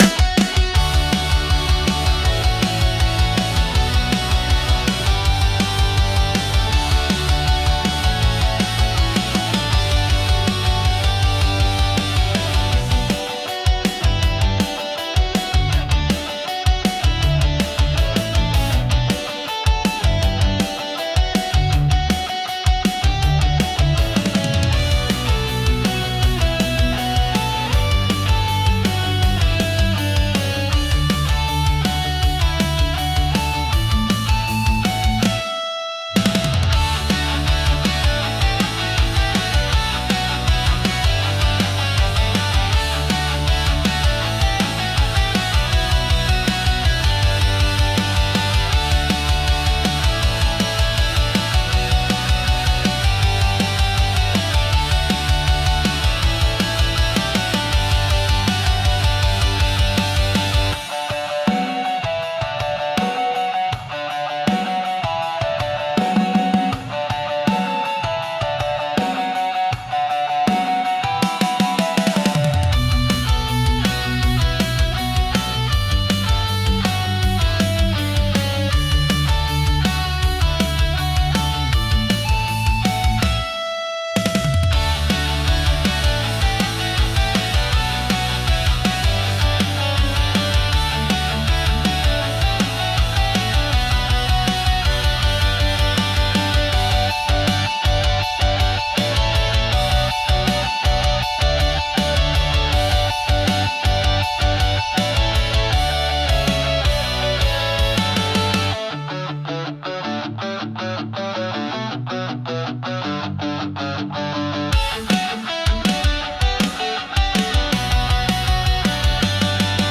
Genre: Pop Punk Mood: High Energy Editor's Choice